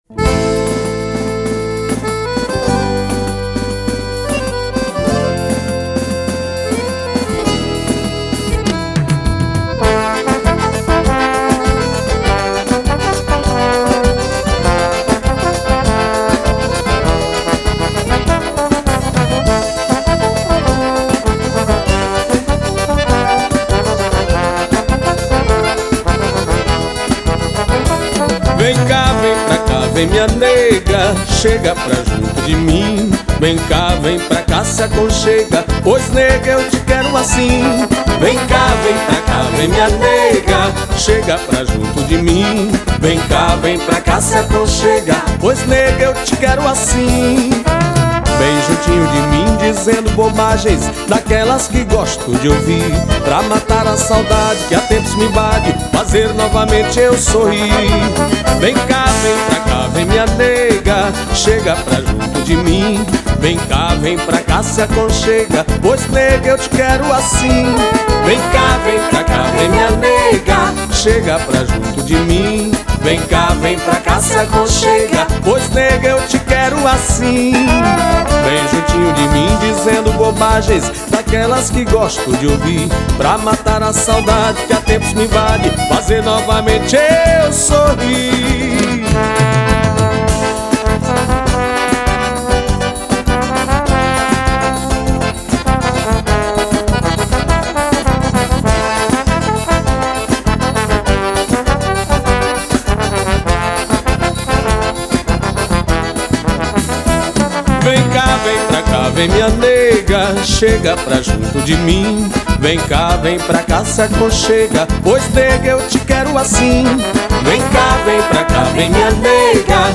2525   02:58:00   Faixa: 4    Baião